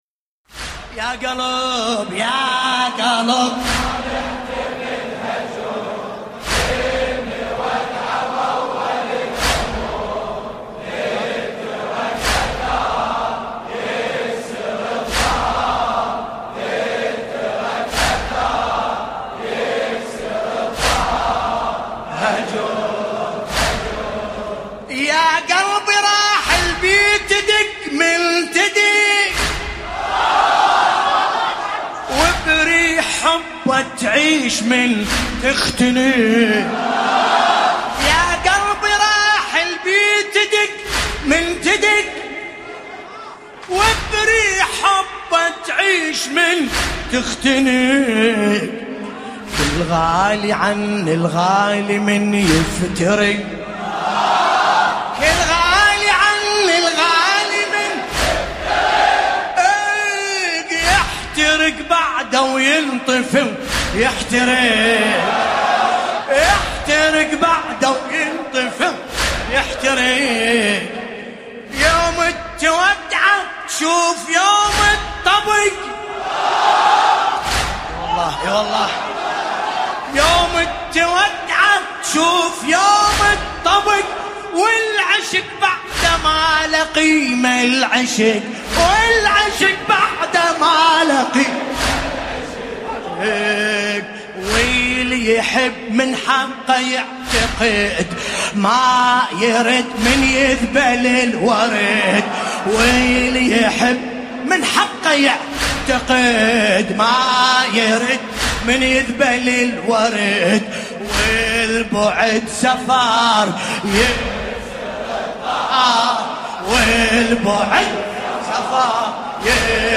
ملف صوتی يا قلب بصوت باسم الكربلائي
الرادود : الحاج ملا باسم الکربلائی ليلة 24 ذي القعدة 1440 | جامع الحاج علي باش بغداد